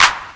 Clap 11.wav